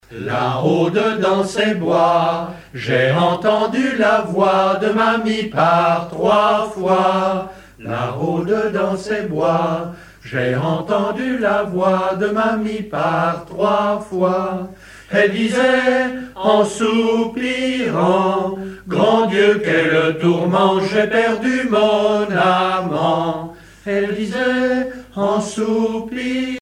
Genre strophique
Catégorie Pièce musicale éditée